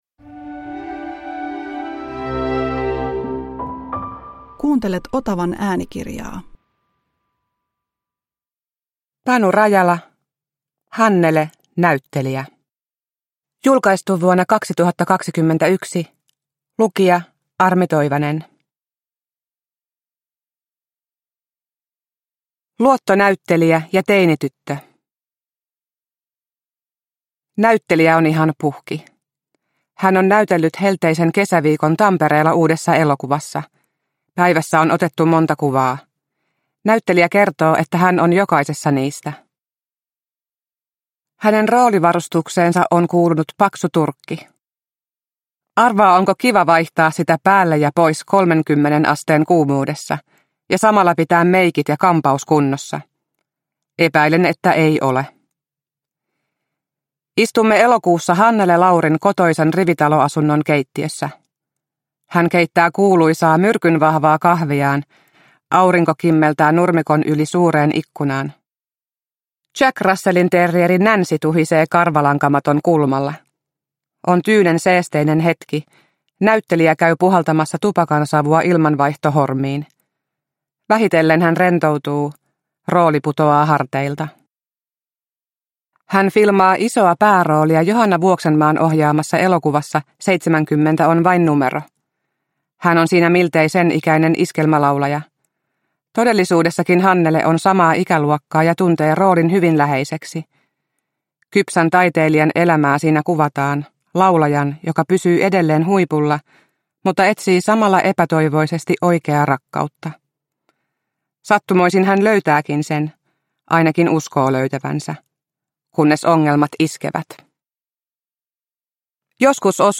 Hannele, näyttelijä – Ljudbok – Laddas ner